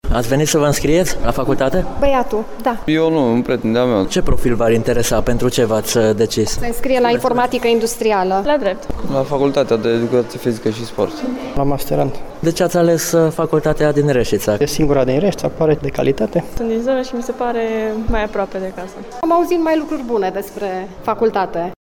s-a aflat astăzi printre absolvenţii de liceu-viitorii studenţi şi a stat de vorbă cu aceştia: